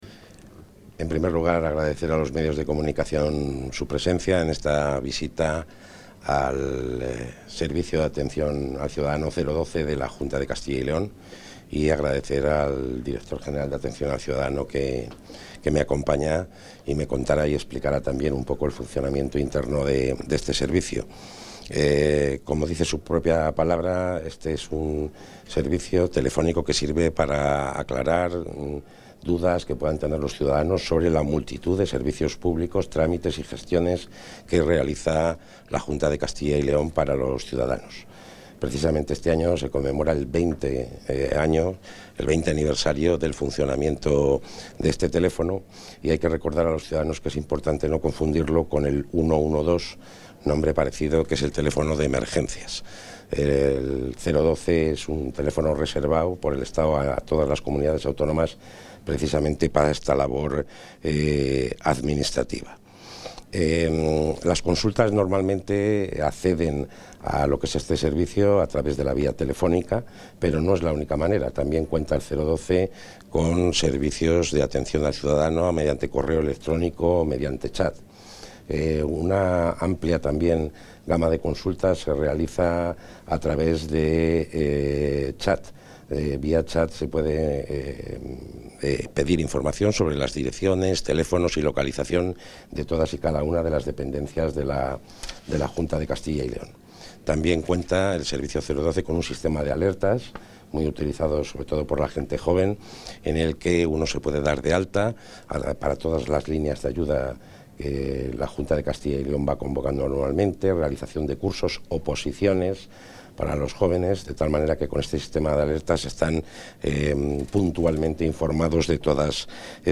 Intervención del consejero.
El Consejero de la Presidencia, Luis Miguel González Gago, ha visitado las instalaciones del Servicio de Atención al Ciudadano 012 de la Junta de Castilla y León, que este 2023 cumple 20 años de funcionamiento.